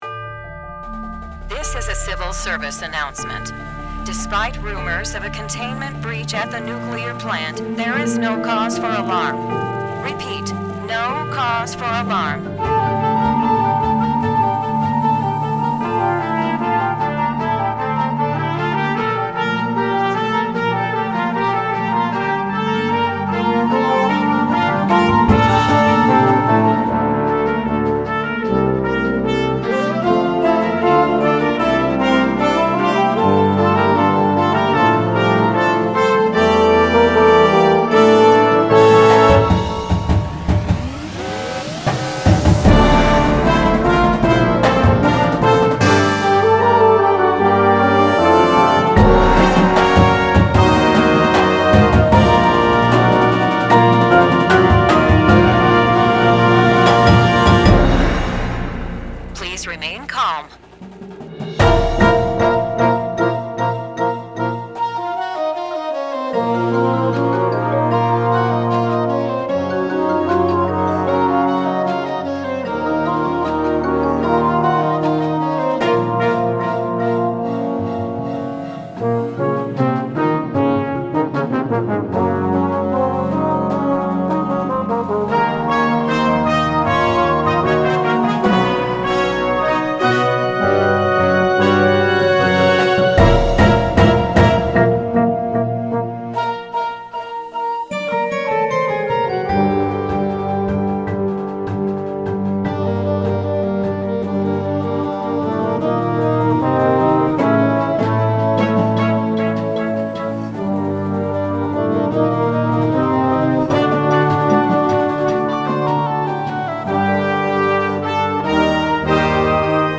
Full Show with Enhanced Percussion, Sound Design & Narration